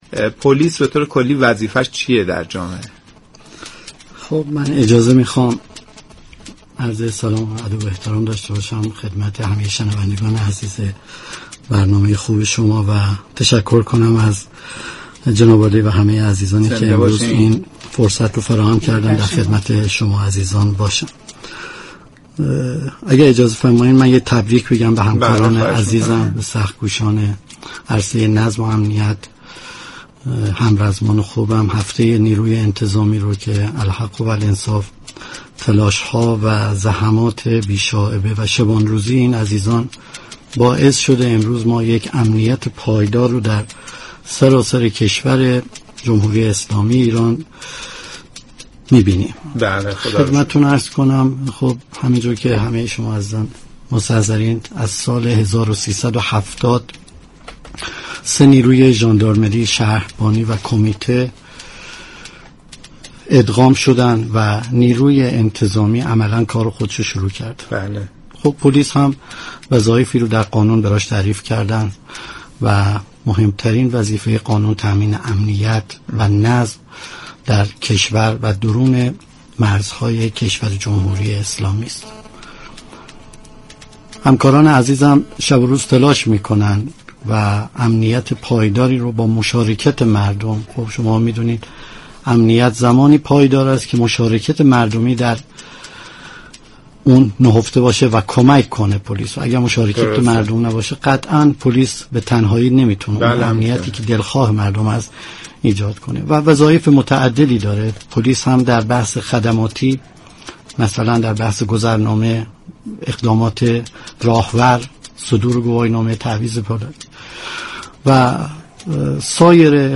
مهمان تحریریه ی مجله ی نیم روز بود
درباره ی وظایف پلیس با ایشان گفتگو كرد